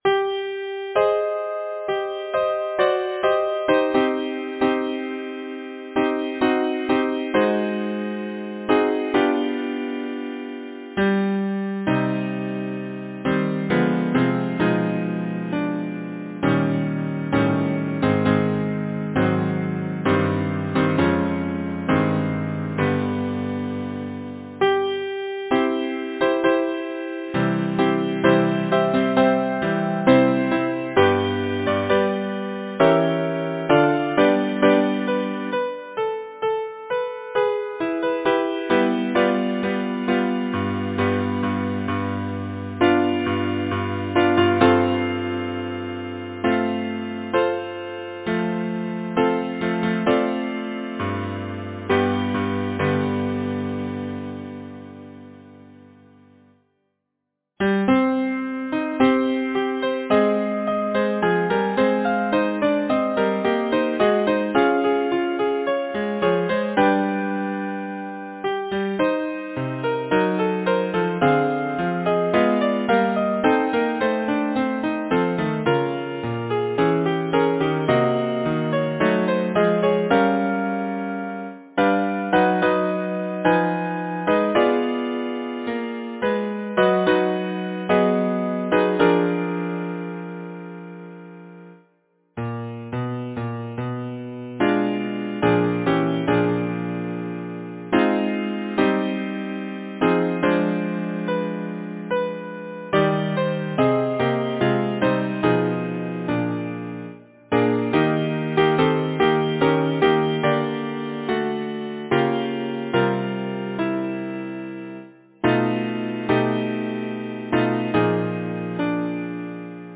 Title: O Little Snowflake Composer: Cyril Jenkins Lyricist: Ella Wheeler Wilcox Number of voices: 4vv Voicing: SATB, divisi Genre: Secular, Partsong
Language: English Instruments: A cappella